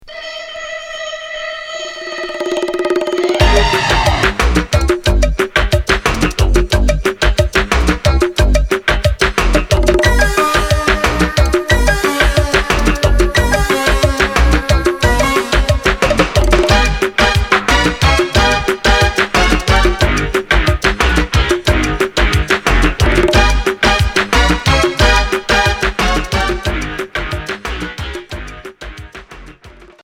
Pop new wave Unique 45t retour à l'accueil